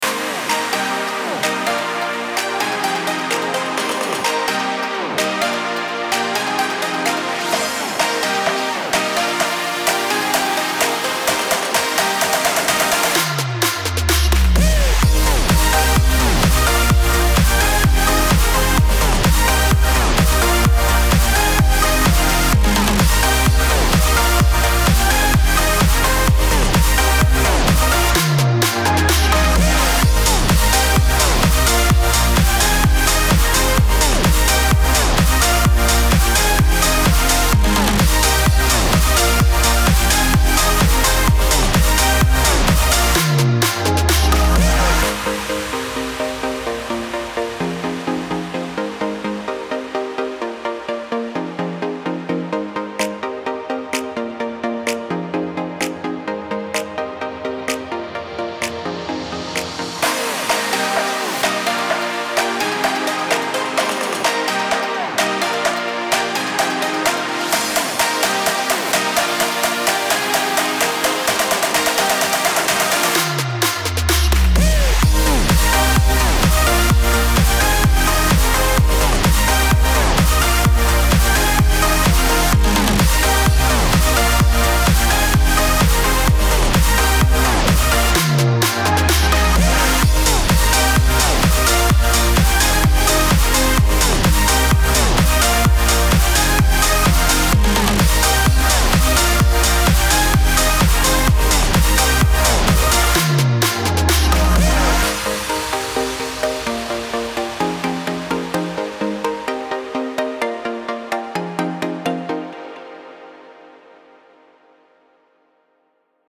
موسیقی بی کلام    الکترونیک